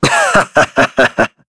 Shakmeh-Vox_Happy3.wav